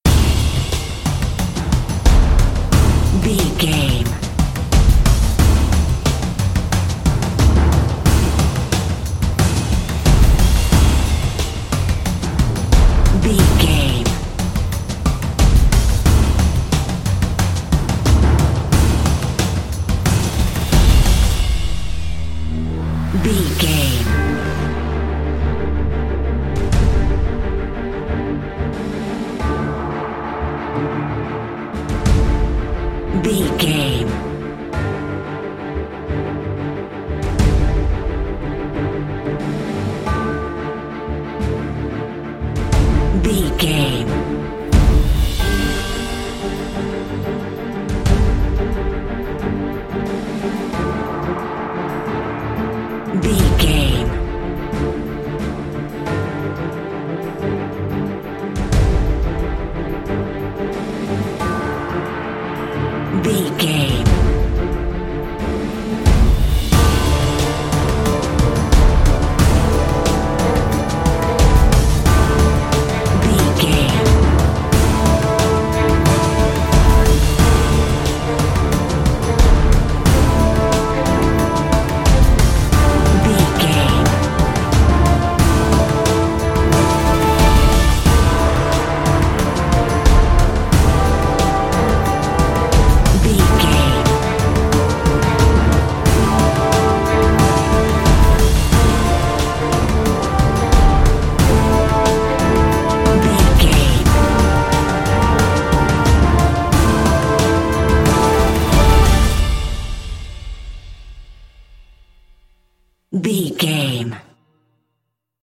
Epic / Action
Aeolian/Minor
D
strings
drums
cello
violin
synthesiser
orchestral hybrid
dubstep
aggressive
energetic
intense
bass
synth effects
wobbles
heroic
driving drum beat